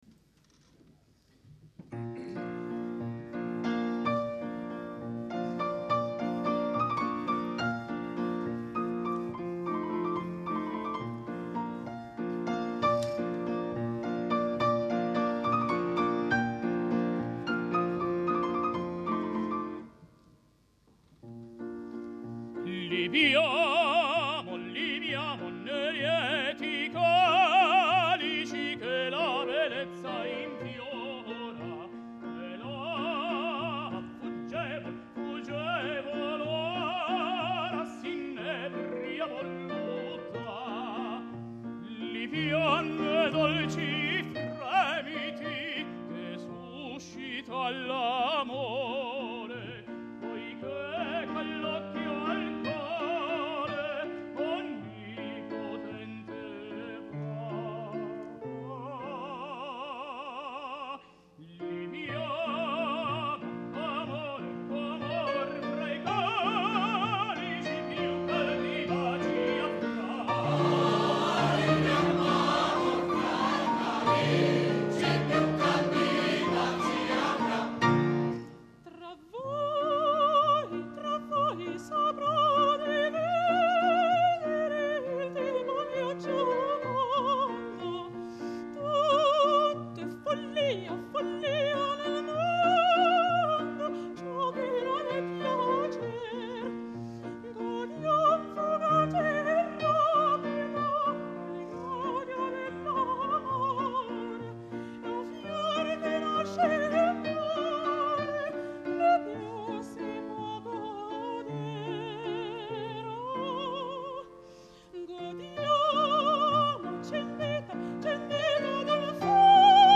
I Brani Musicali sono stati registrati al Teatro "A. Bonci" di Cesena il 18 Febbraio 2001 durante il
CONCERTO LIRICO
CORALE BANDISTICO
Il Coro Lirico Città di Cesena
Banda "Città di Cesena"